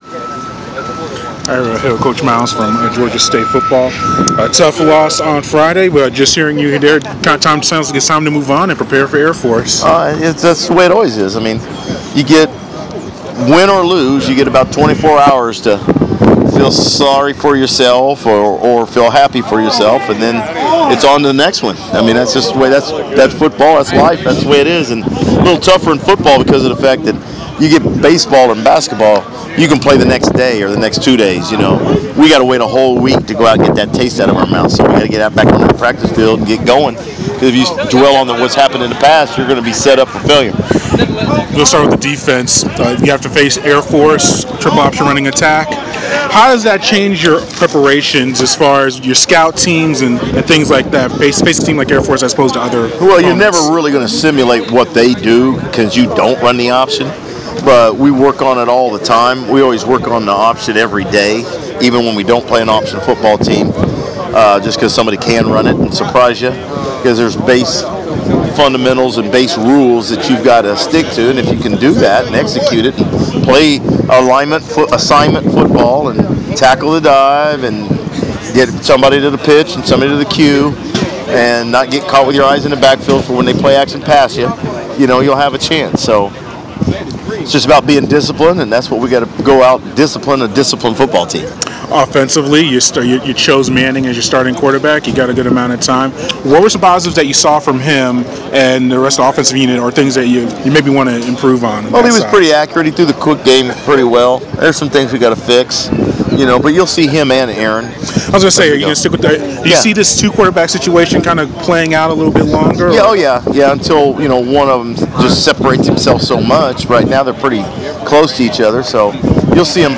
Georgia State